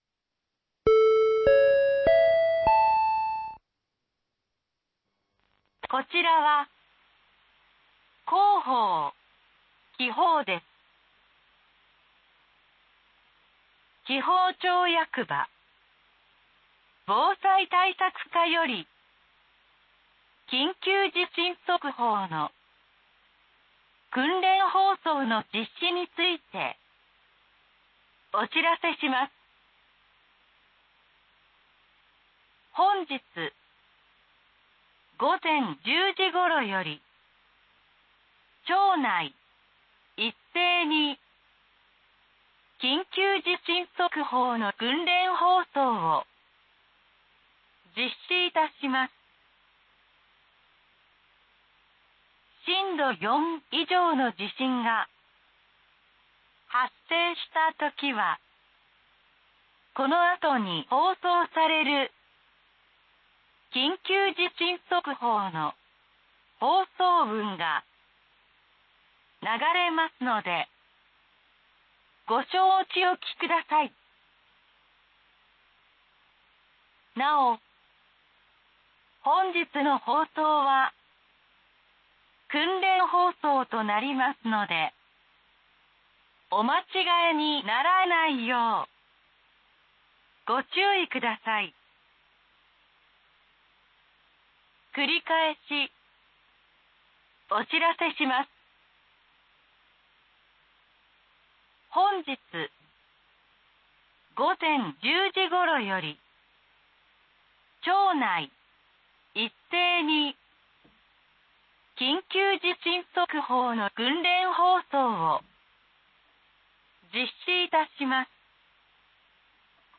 本日、午前１０時頃より、町内一斉に緊急地震速報の訓練放送を実施いたします。